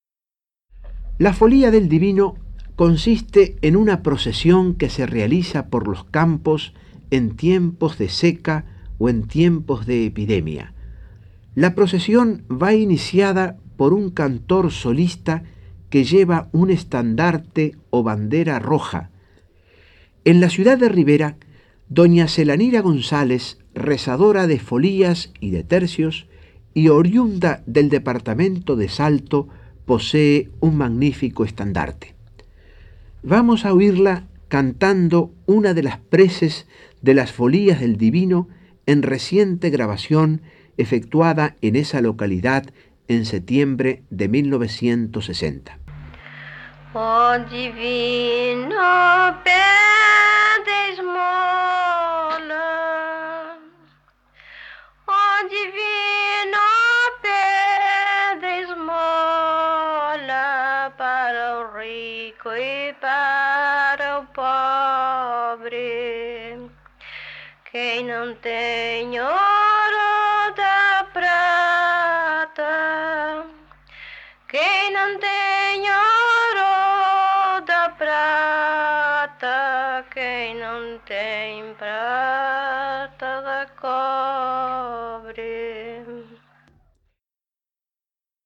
Locución: Lauro Ayestarán
Grabación de campo emitida en la audición y utilizada en este micro radial:
Especie: folías del divino